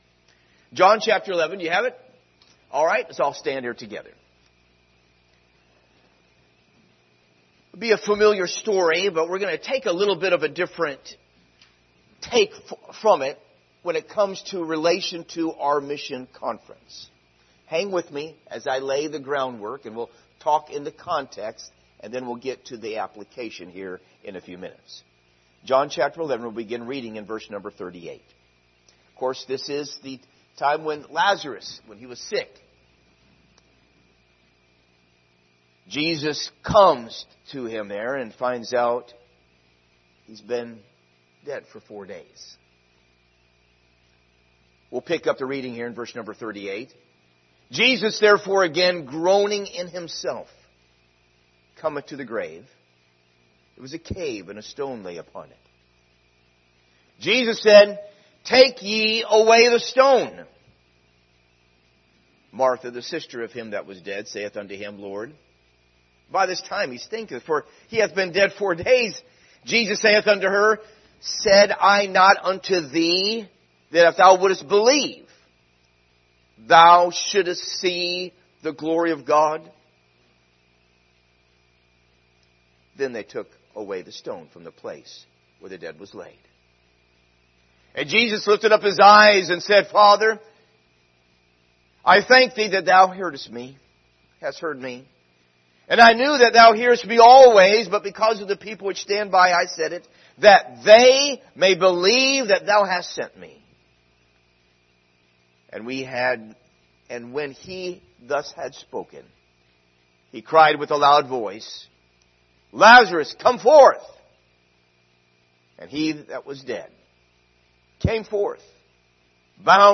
Speaker: Missionary Speaker Series: 2025 Missions Conference Passage: John 11:38-44 Service Type: Special Service